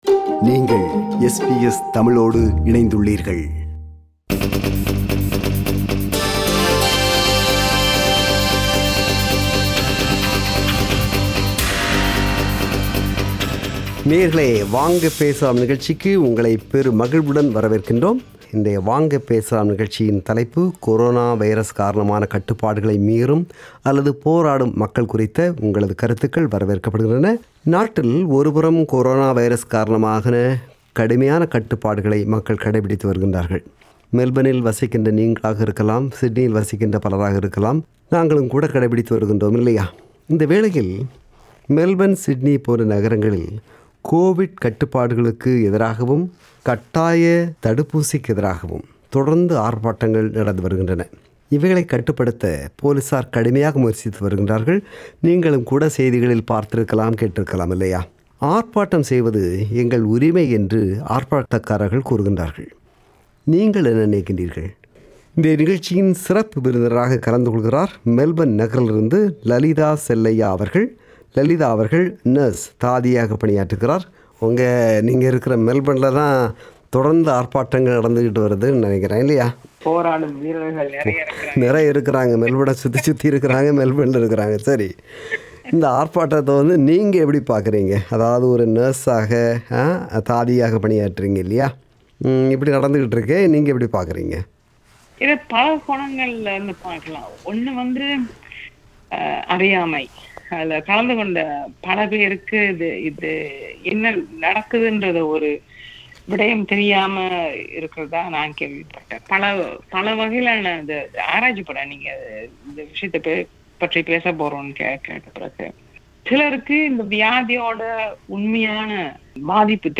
“வாங்க பேசலாம்” நிகழ்ச்சியில் ஒலித்த கருத்துக்களின் தொகுப்பு.